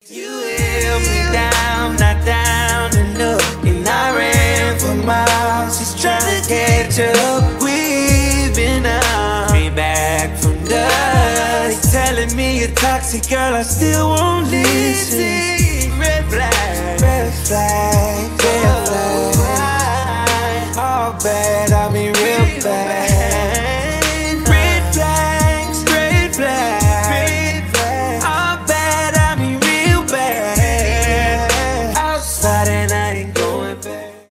рэп , rnb
хип-хоп
соул